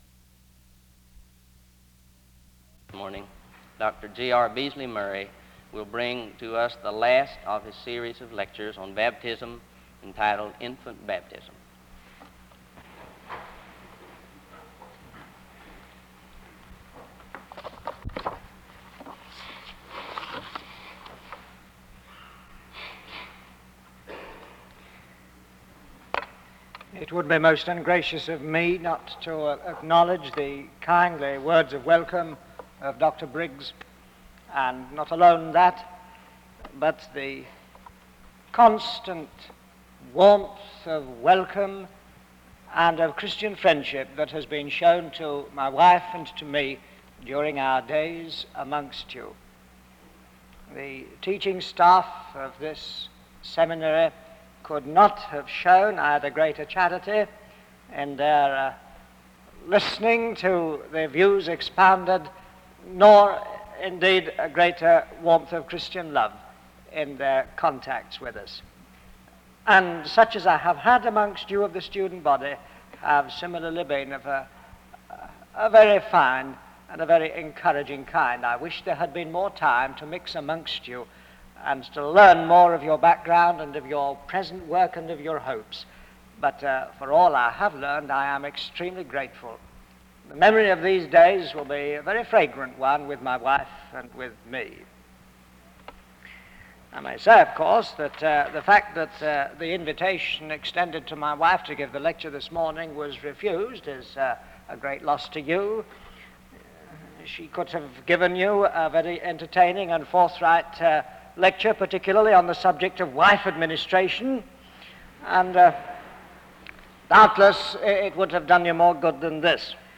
SEBTS_Chapel_George_R_Beasley-Murray_1959-03-13.wav